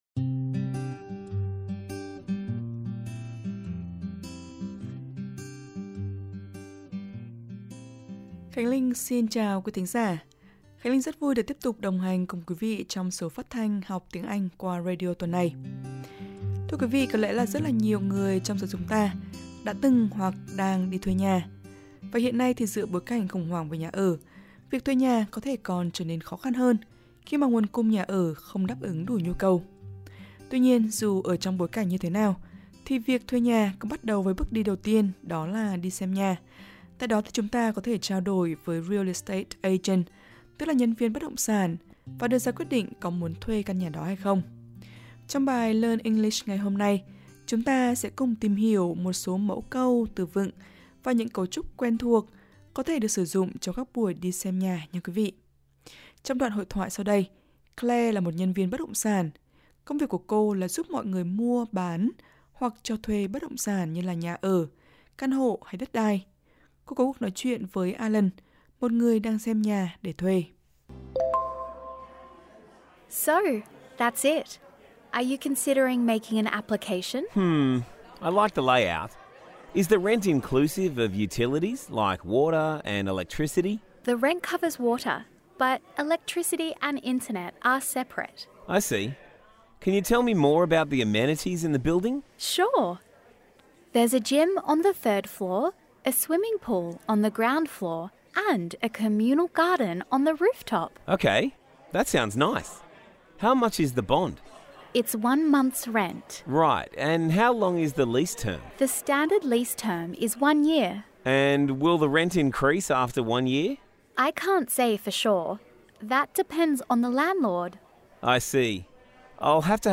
Trong bài Learn English hôm nay, chúng ta sẽ cùng tìm hiểu một số mẫu câu, từ vựng, và cấu trúc quen thuộc thường được dùng trong các buổi đi xem nhà. Trong đoạn hội thoại sau đây, Claire là một nhân viên bất động sản, công việc của cô là giúp mọi người mua, bán hoặc cho thuê bất động sản như nhà, căn hộ hoặc đất đai. Cô có cuộc nói chuyện với Allan, một người đang xem nhà để thuê.